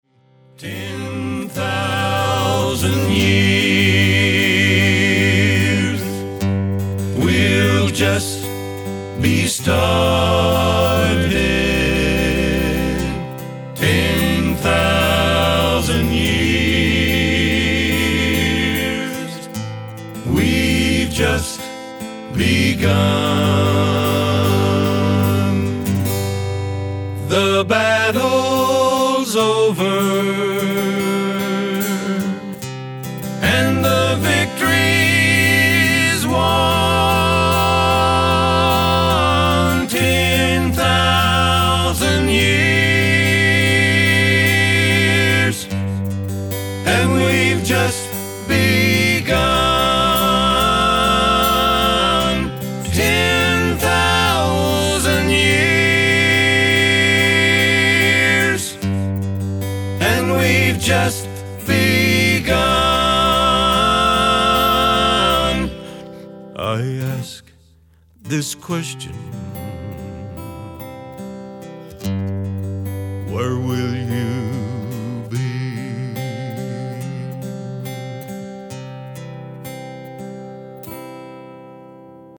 Autoharp, Lead & Harmony Vocals
Drums, Lead & Harmony Vocals
Keyboards, Bass Vocals